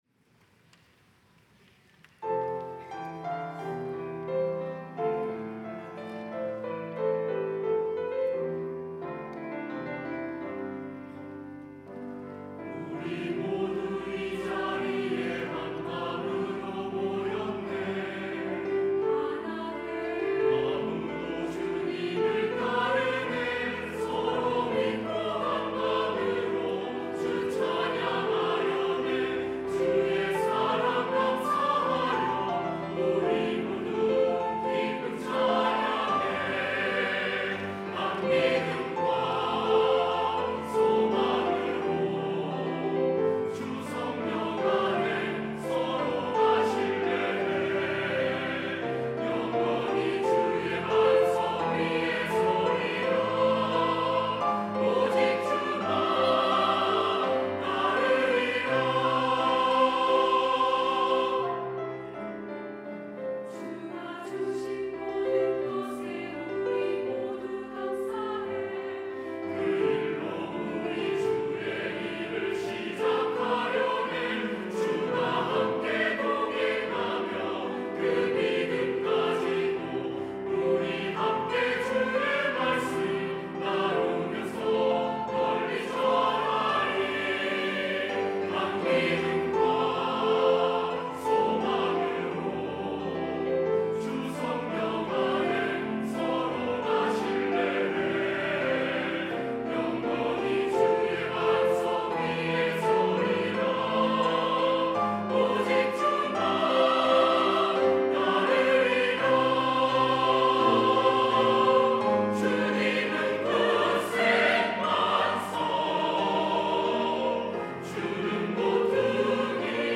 할렐루야(주일2부) - 한 믿음 가지고
찬양대